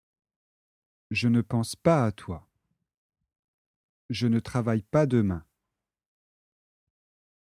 L'intonation monte sur "pas".  L’intonation baisse en fin de phrase.